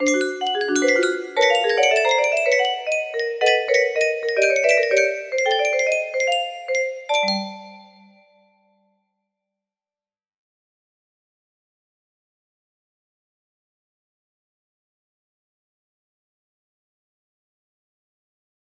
lois 2 music box melody